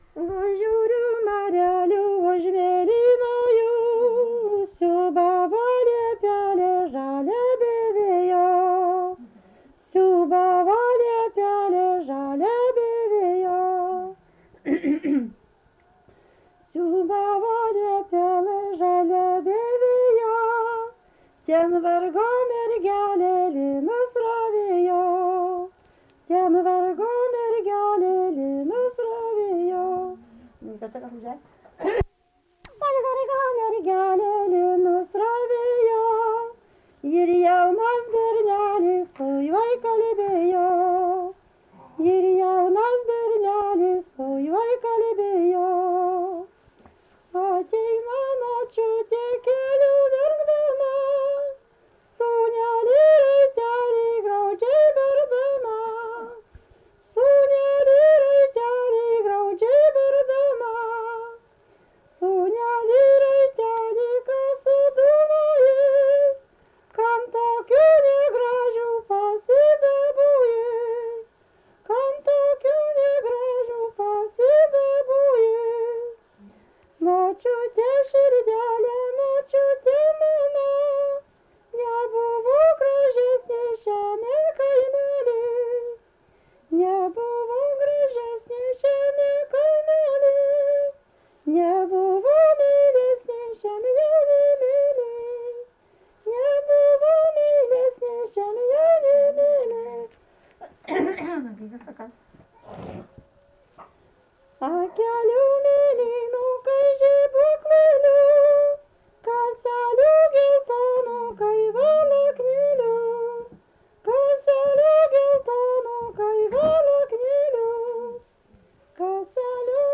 Subject daina
Erdvinė aprėptis Druskininkai
Atlikimo pubūdis vokalinis